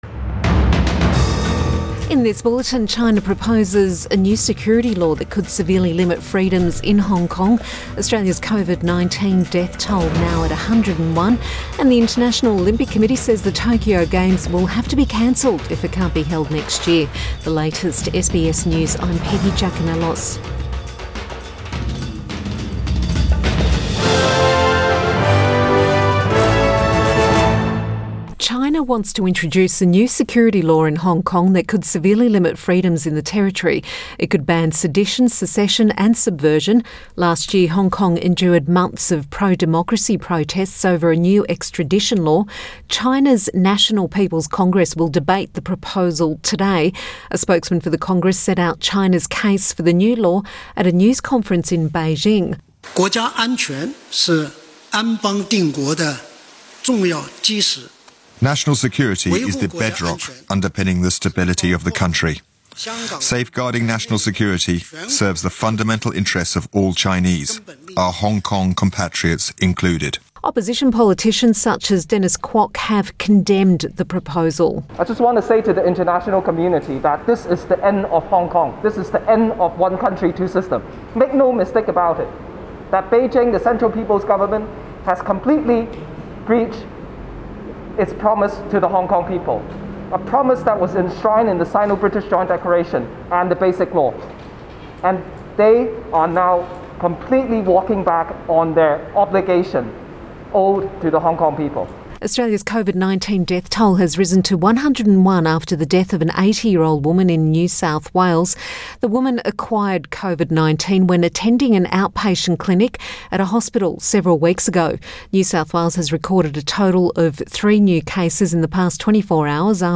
Midday bulletin May 22 2020